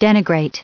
added pronounciation and merriam webster audio
201_denigrate.ogg